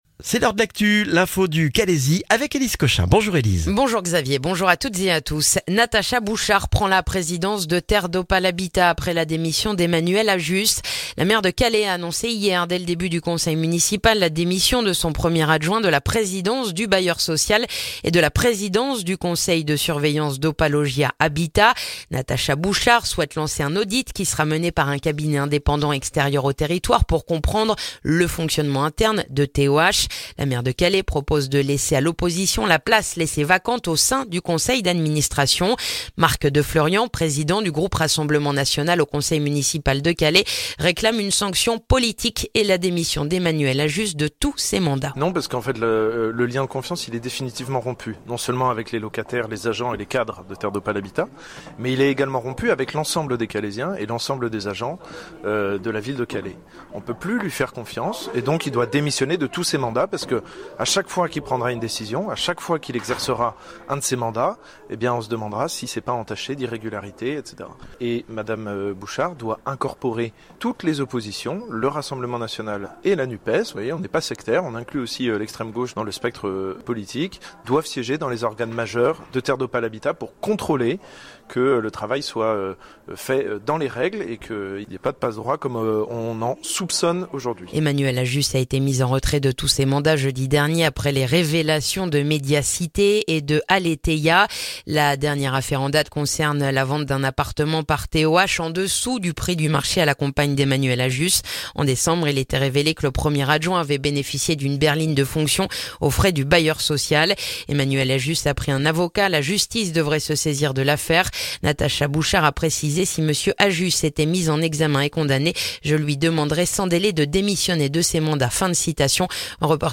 Le journal du mercredi 6 février dans le calaisis